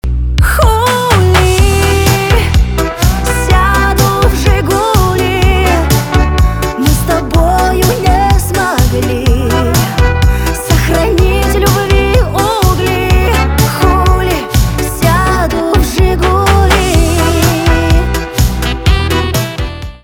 Русские рингтоны
поп